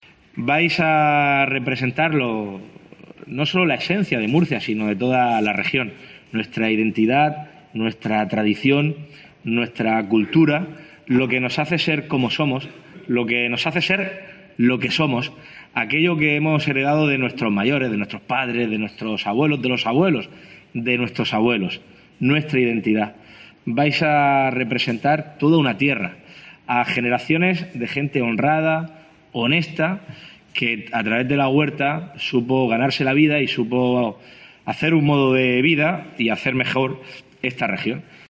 El presidente de la Región ha recibido a las Reinas de la Huerta en San Esteban
Fernando López Miras, presidente de la Región de Murcia